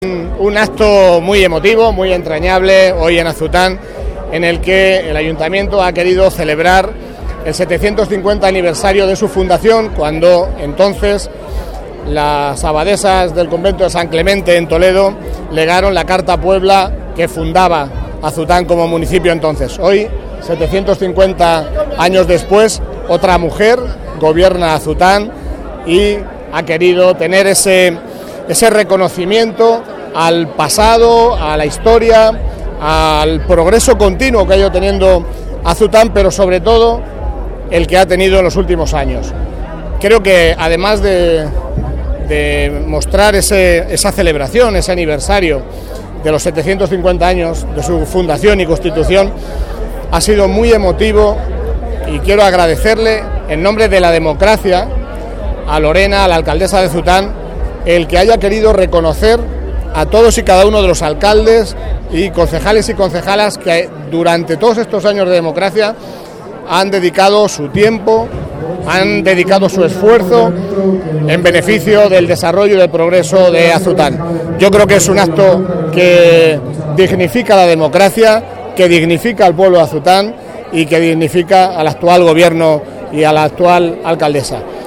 Delegación Provincial de la Junta de Comunidades de Castilla-La Mancha en Toledo Sábado, 11 Mayo 2024 - 4:00pm Álvaro Gutiérrez ha participado en el acto conmemorativo del 750 Aniversario de la localidad que se ha celebrado hoy corte_750aniversarioazutan_alvarogutierrez.mp3 Descargar: Descargar Provincia: Toledo